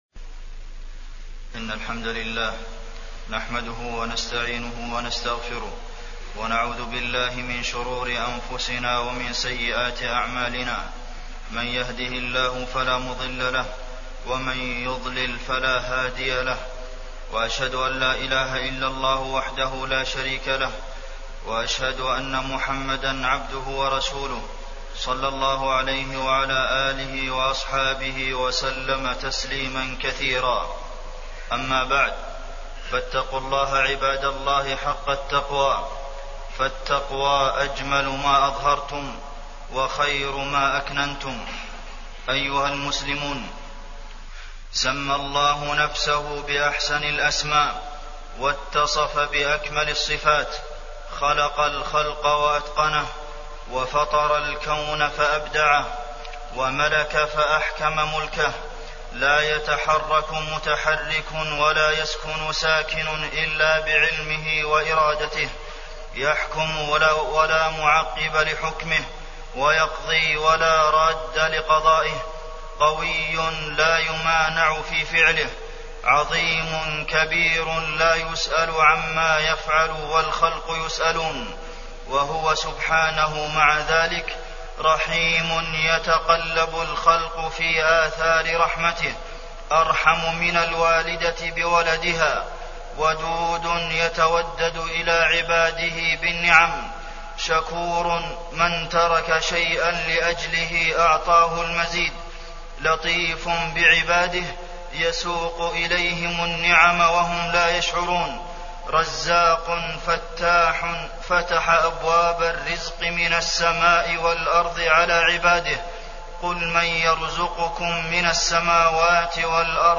تاريخ النشر ١٢ ربيع الثاني ١٤٢٩ هـ المكان: المسجد النبوي الشيخ: فضيلة الشيخ د. عبدالمحسن بن محمد القاسم فضيلة الشيخ د. عبدالمحسن بن محمد القاسم رحمة الله بعبادة